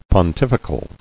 [pon·tif·i·cal]